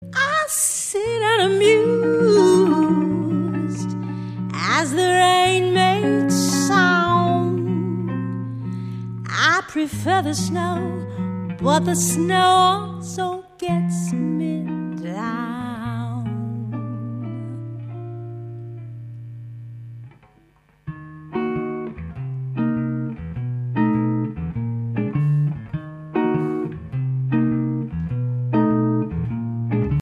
voce
Una voce di velluto tagliente.